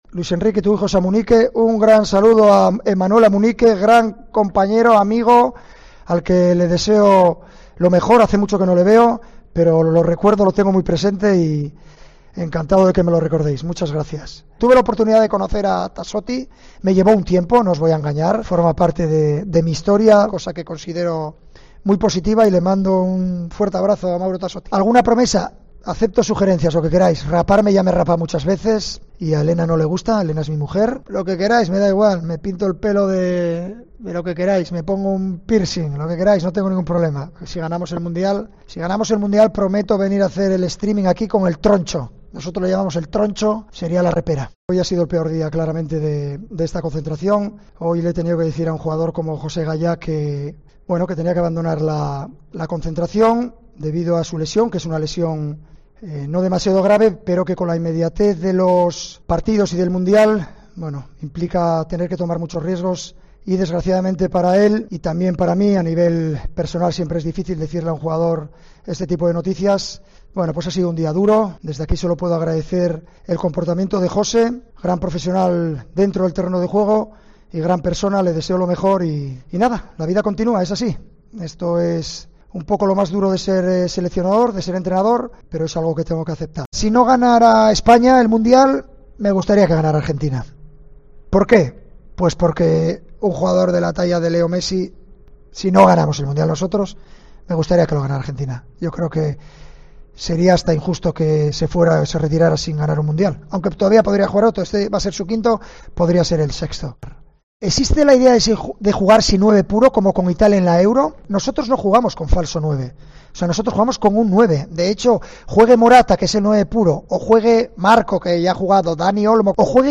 Repasa los mejores momento del primer directo de Luis Enrique
El seleccionado nacional Luis Enrique Martínez se ha estrenado este viernes con sus directos en Twitch y ha comenzado con un tono de humor debido a los comentarios de los seguidores en el chat ya que mucha gente se dirigía al entrenador del combinado español como Luis Padrique y el técnico se lo ha tomado con gracia: "Veo mucho Padrique, me encanta Padrique, me gusta mucho Luis Padrique".